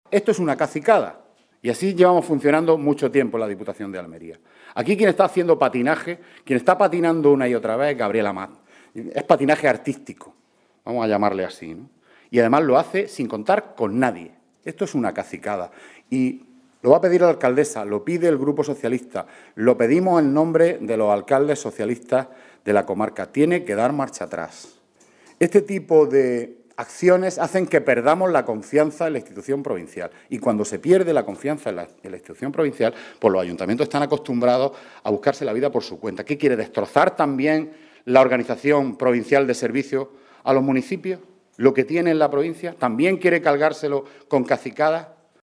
Rueda de prensa que ha ofrecido la alcaldesa de Cantoria, Purificación Sánchez